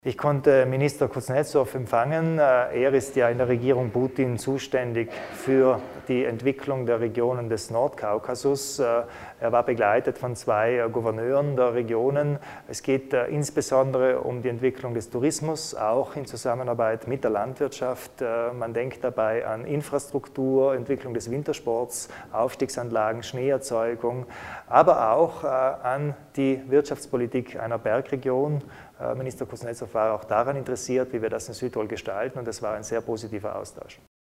Landeshauptmann Kompatscher zur Bedeutung der russischen Märkte